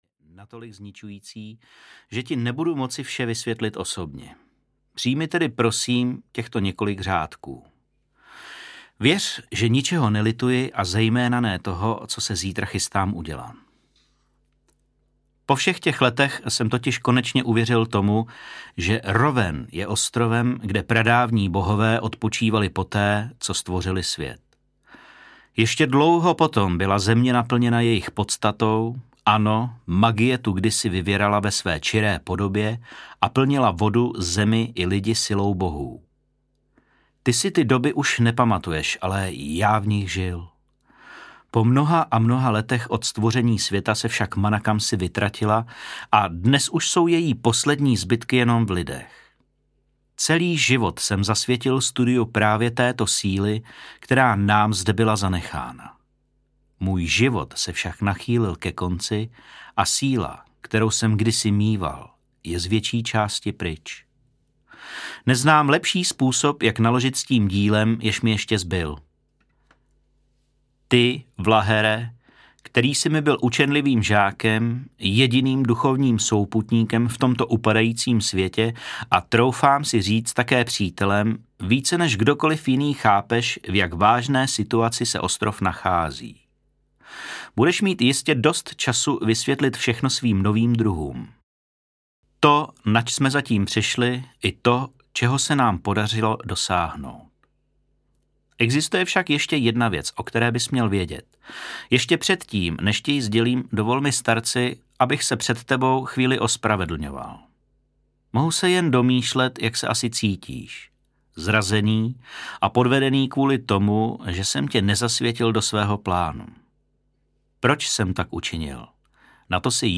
Brány Skeldalu: Bílá věž audiokniha
Ukázka z knihy